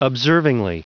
Prononciation du mot observingly en anglais (fichier audio)
Prononciation du mot : observingly